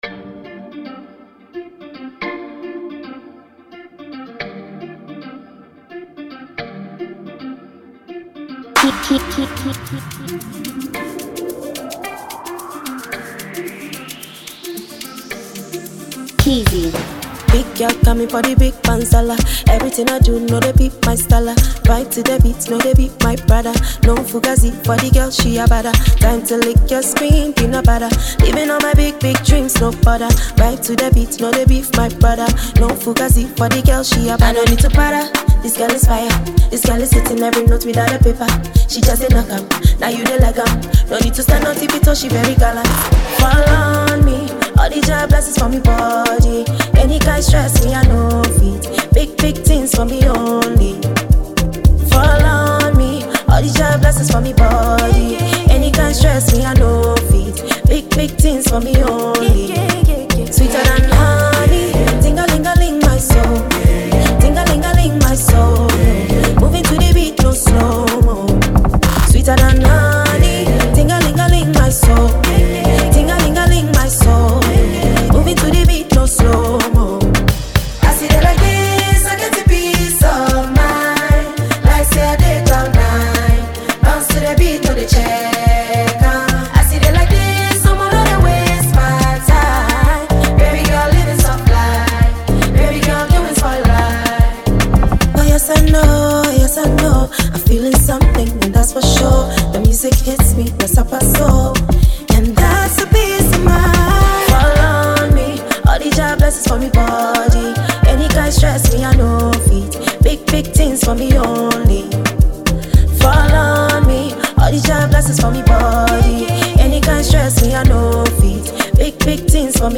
a Ghanaian songstress